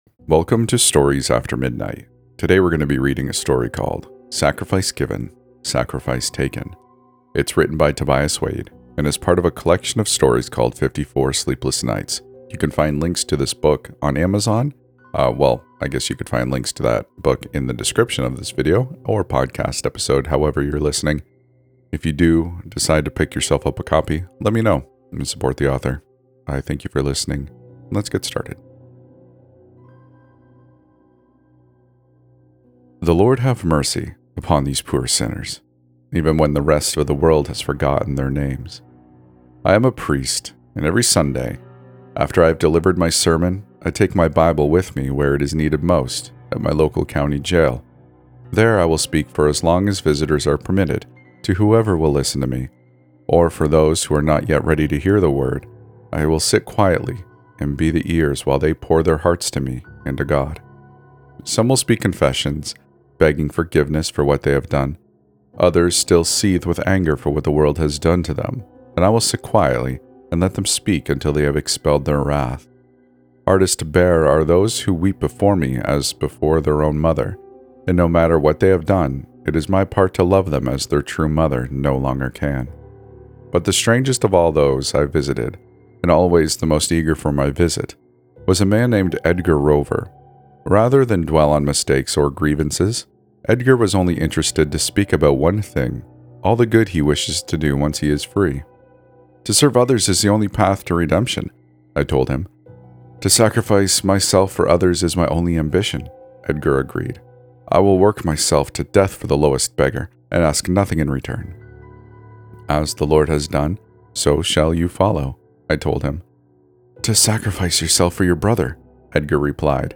Story: "Sacrifice Given, Sacrifice Taken" from the book 54 Sleepless Nights Grab your copy!